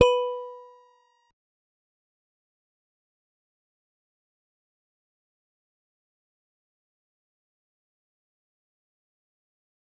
G_Musicbox-B4-mf.wav